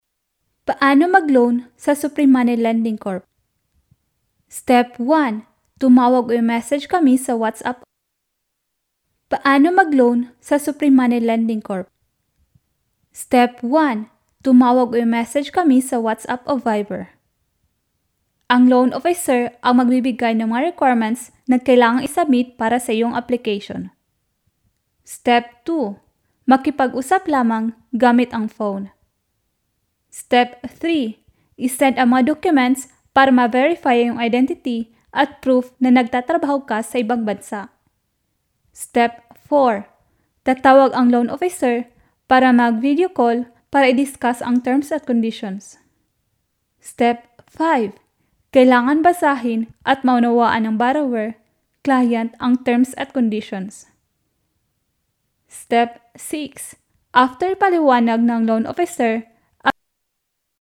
女国112_广告_手机通信_OPPO手机_时尚-新声库配音网
配音风格： 大气 知性 时尚 稳重 亲切 轻松 年轻 活力 力度 温柔 力度 温柔